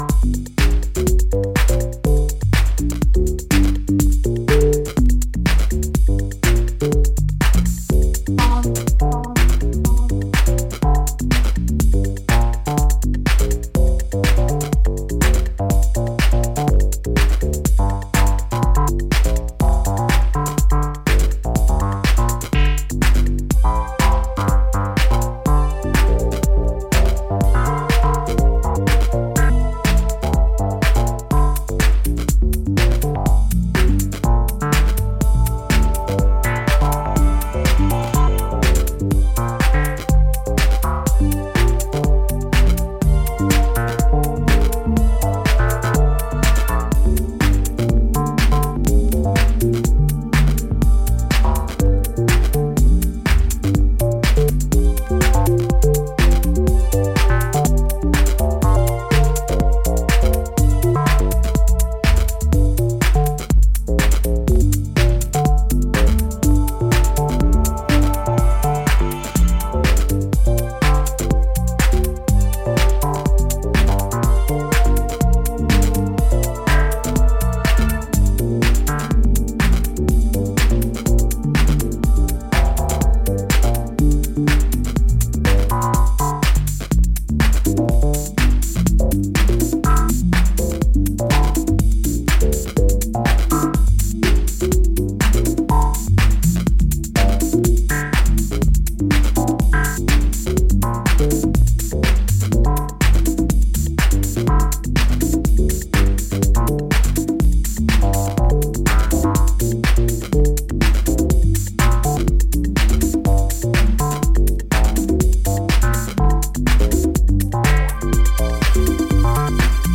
Detroit dreams-led, bassline tough house jams.
Deep house